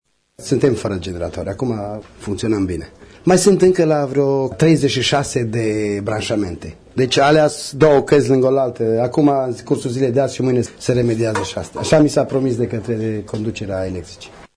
Primarul comunei Cuci, Ilie Șuta, a declarat pentru RTM că, în prezent aproximativ 98% din casele afectate au curent electric şi s-a putut renunţa la cele 3 generatoare de mare putere aduse de la Brașov: